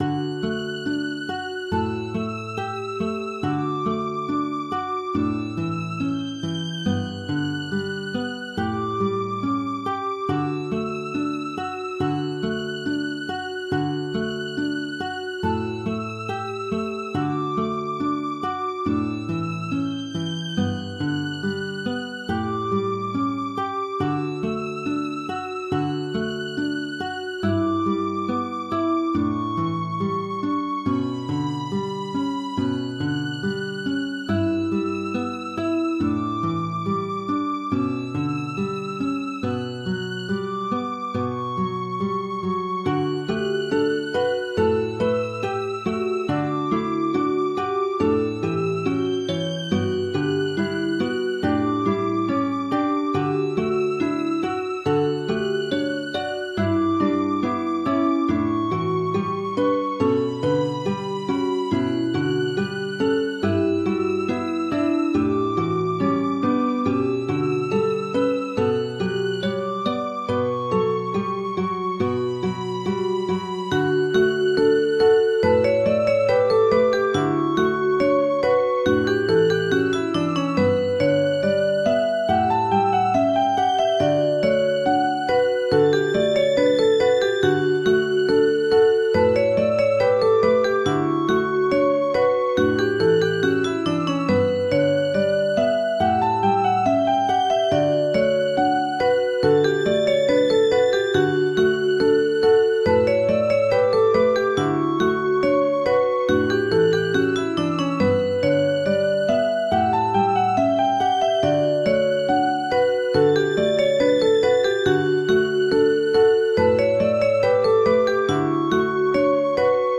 - Add ambiance tracks for entrepot, atelier and cour zones